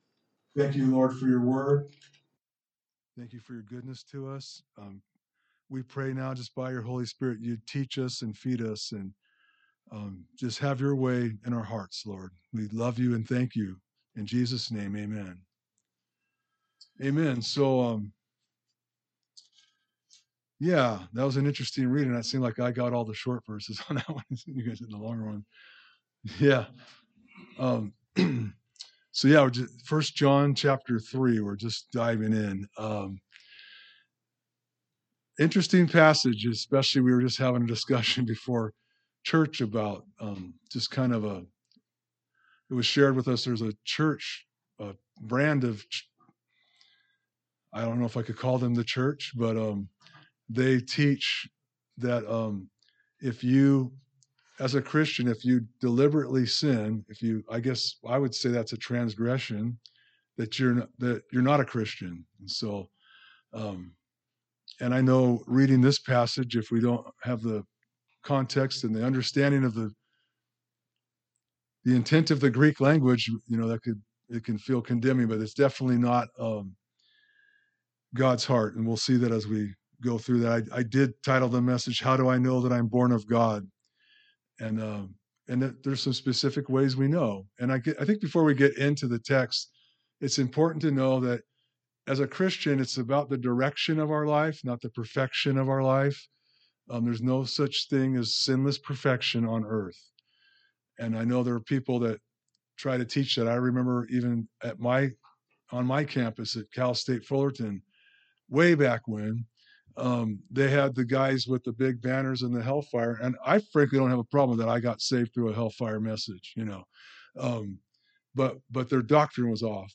A message from the series "1 John."